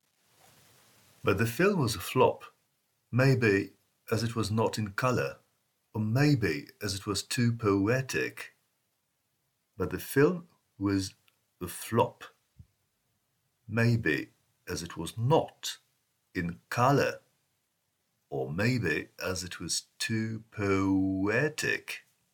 Pronunciation : the letter O :